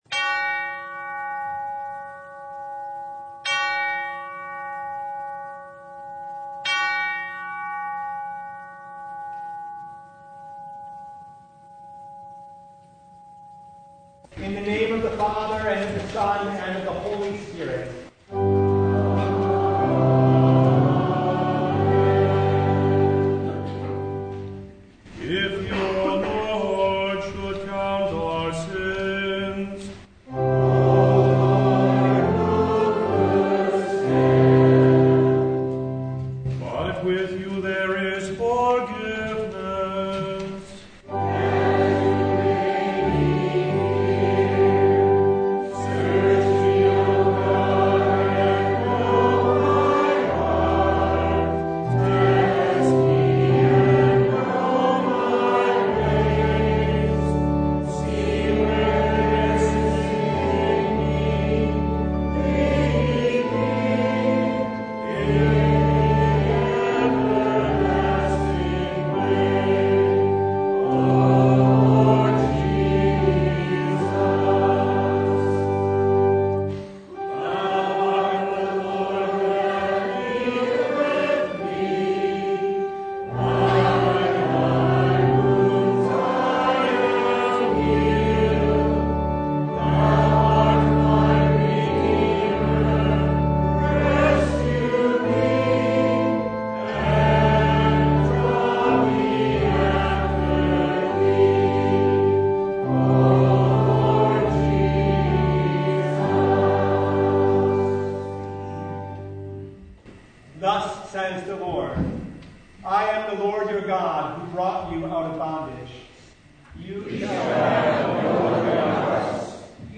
John 3:1-17 Service Type: Sunday How can one see and enter the kingdom of God?